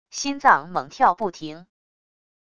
心脏猛跳不停wav音频